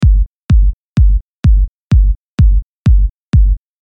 но это отчетливо слышно, что первый удар громче всех остальных походу это мультибенд компрессор\лимитер, сжерает только низ потом вот как звучит первый и второй удар бочки
kicks.mp3